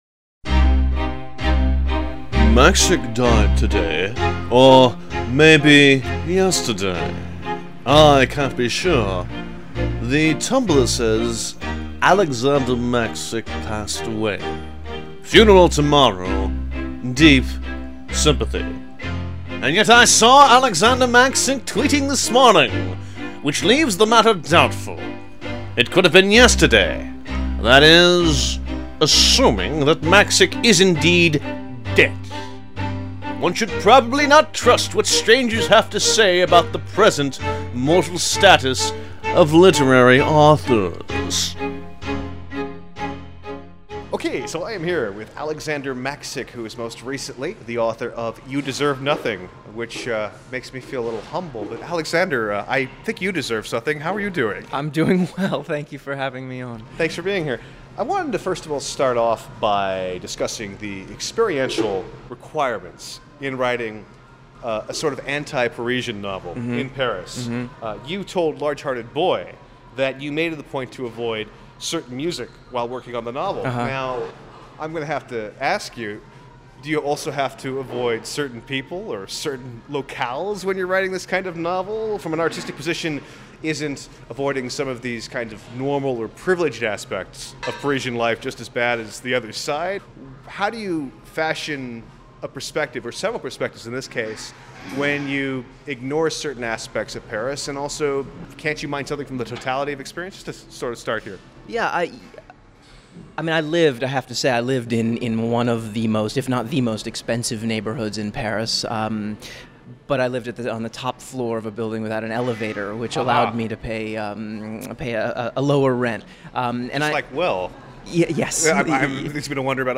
In this vivacious 35 minute radio interview, filmmaker Nick Broomfield discusses Sarah Palin: You Betcha, the amateurist aesthetic, moral paralysis, paying documentary subjects, Lily Tomlin, and conservative politicians with big hair.